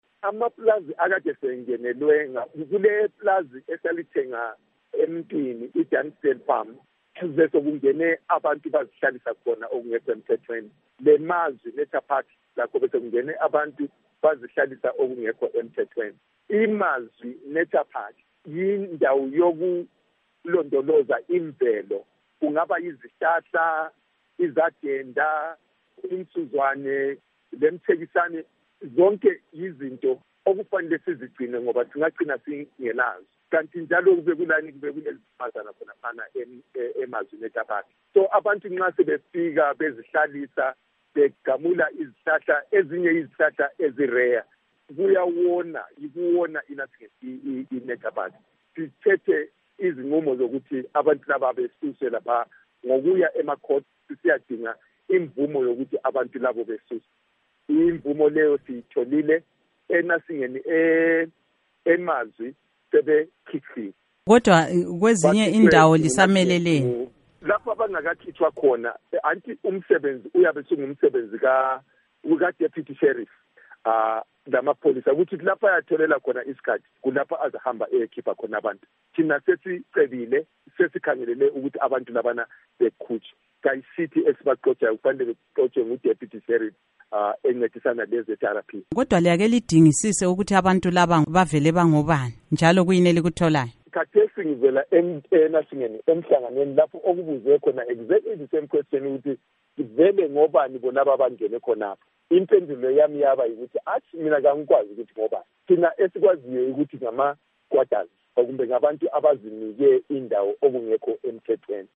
Ingxoxo LoKhansila Martin Moyo